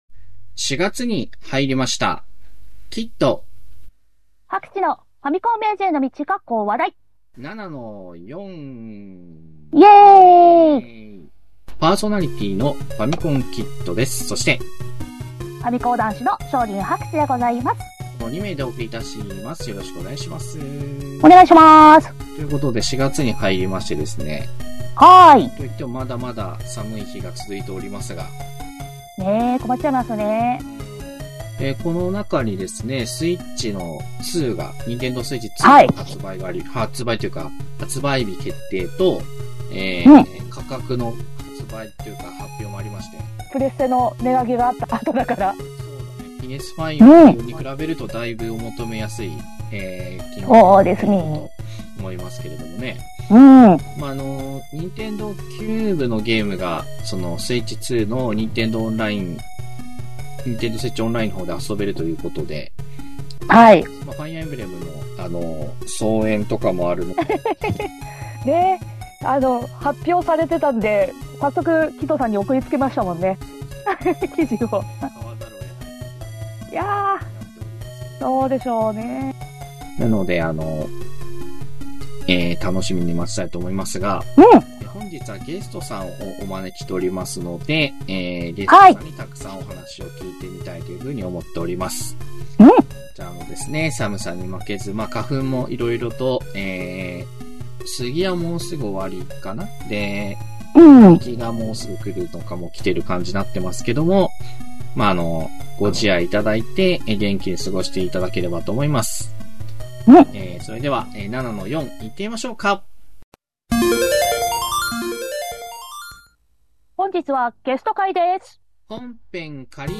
昭和っぽいテイストのインターネットラジオ